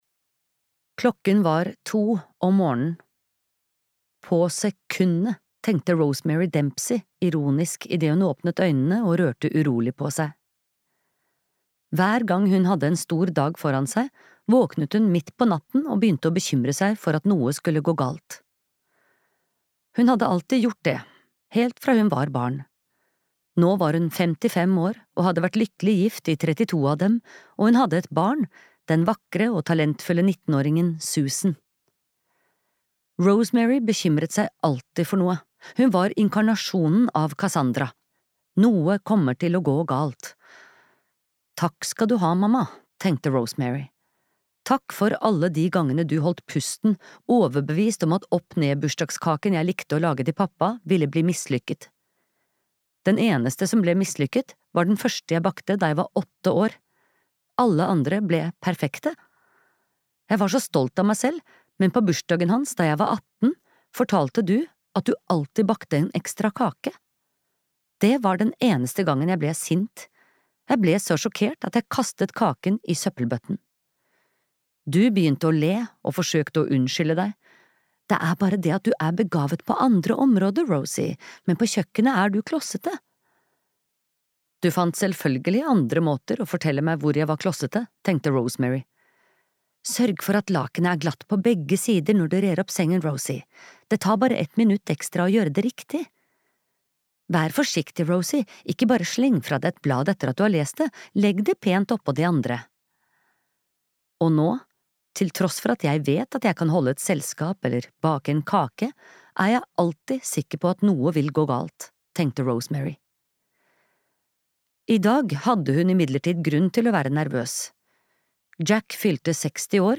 Askepottmordet (lydbok) av Mary Higgins Clark